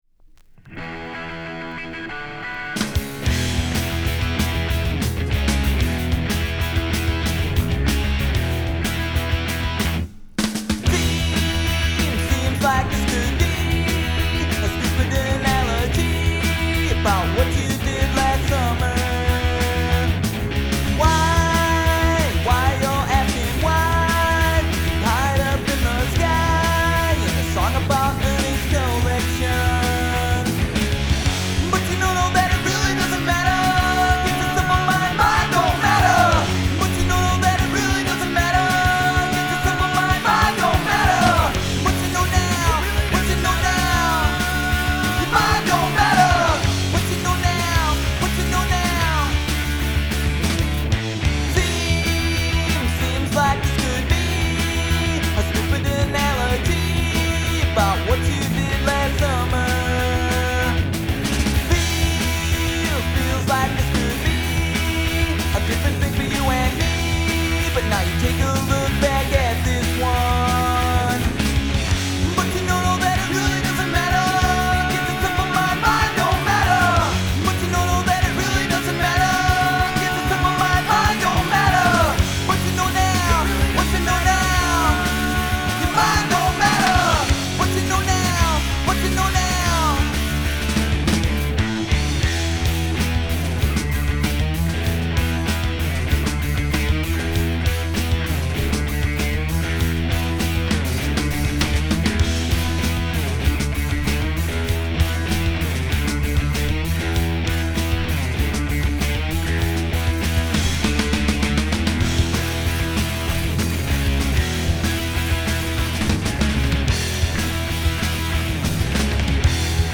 Vocals/Guitar
Bass
Drums
Pop-Punk